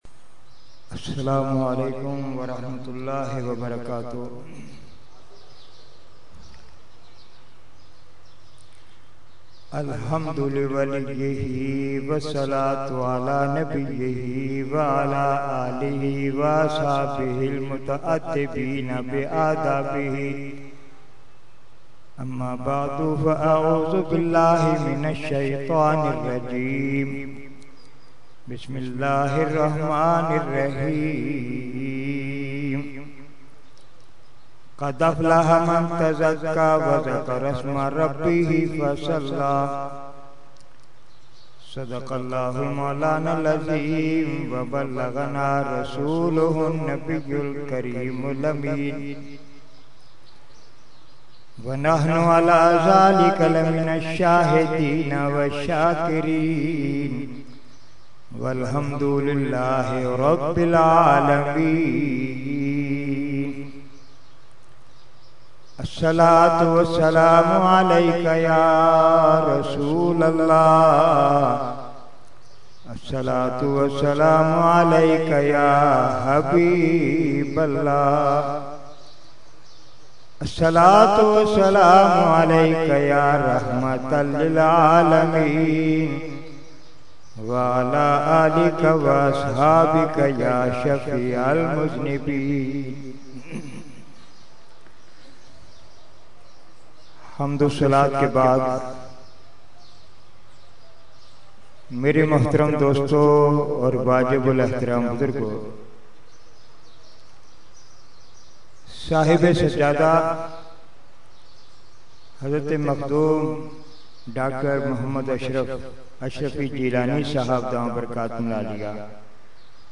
Category : Speech | Language : UrduEvent : Urs Qutbe Rabbani 2012